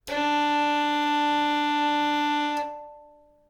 That distance was adjusted to have, qualitatively, a strong direct sound with a bit of reverberation, in a quiet laboratory room.
. Audio file Violin5_BowingMachine_D